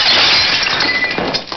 Glassbreak Die of Death - Bouton d'effet sonore